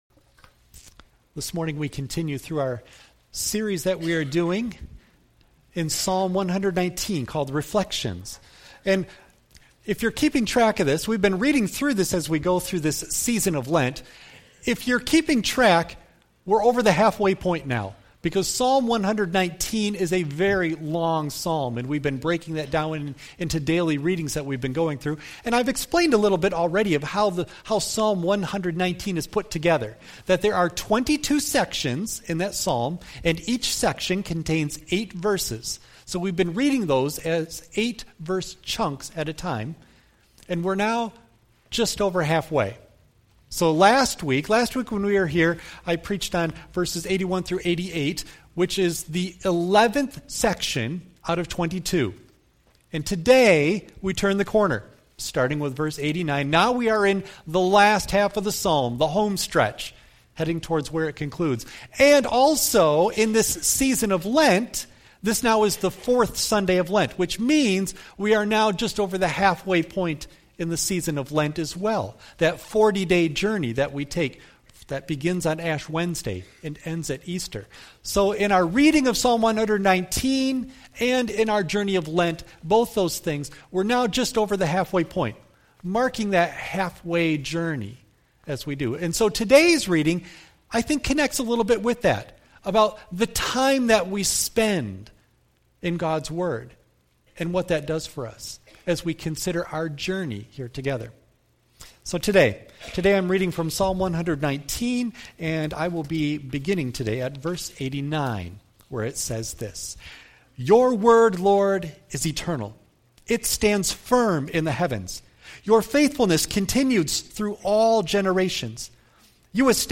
89-96 Service Type: Sunday AM Bible Text